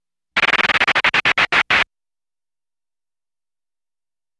Звуки сломанной машины
Мультяшный звук: мотор машины сломался